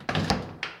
crateOpen.ogg